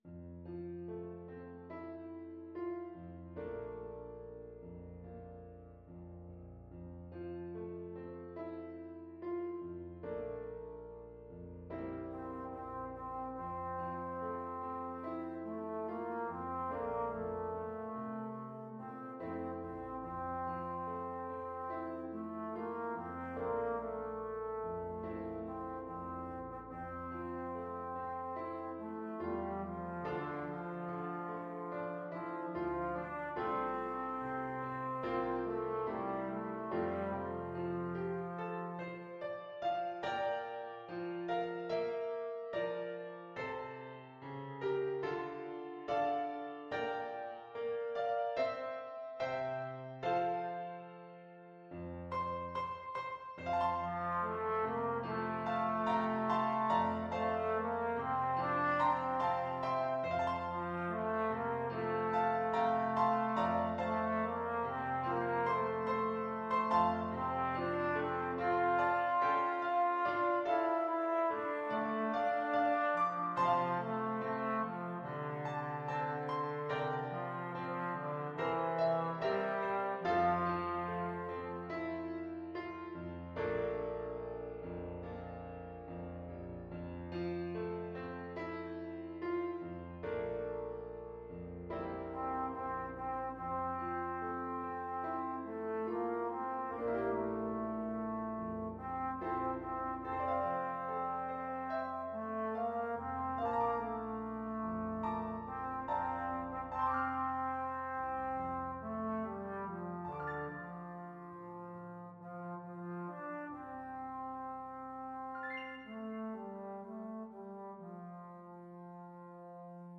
Trombone
4/4 (View more 4/4 Music)
F major (Sounding Pitch) (View more F major Music for Trombone )
Molto espressivo =c.72